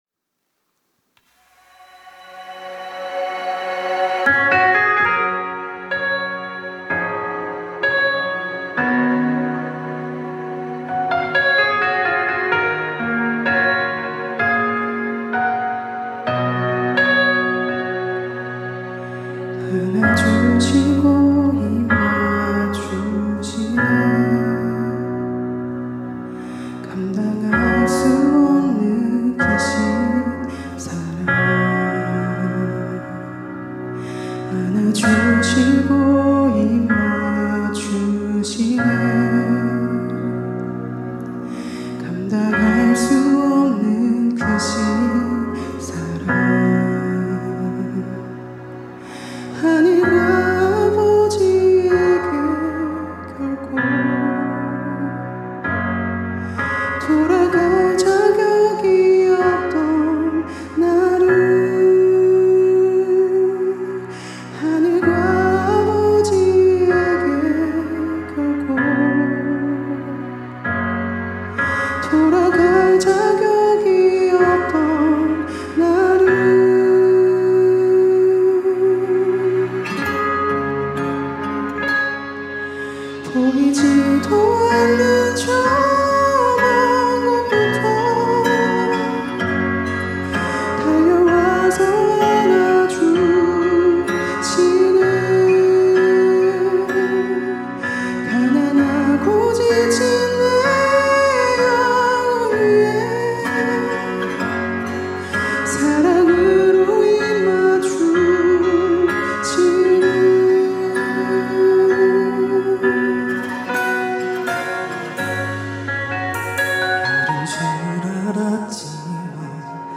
특송과 특주 - 회중찬양